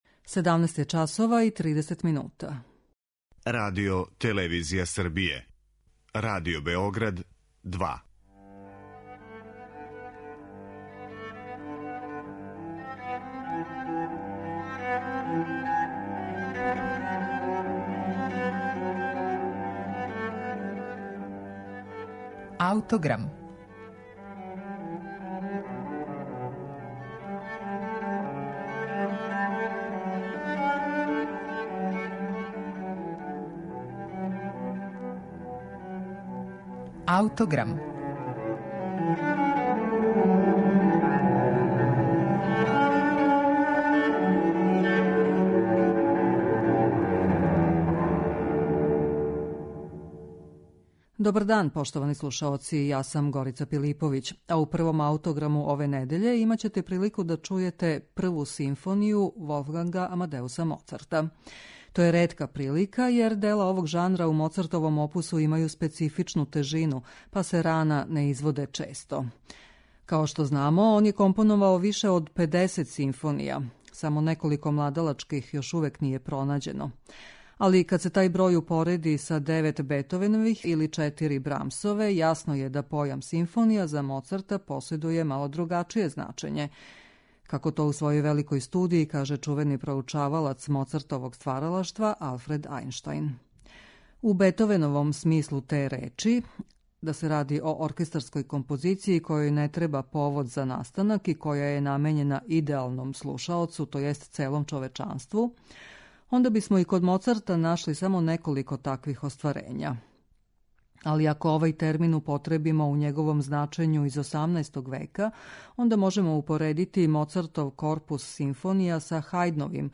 МОЦАРТОВА ПРВА СИМФОНИЈА